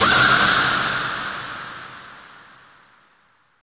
Index of /90_sSampleCDs/Houseworx/12 Vocals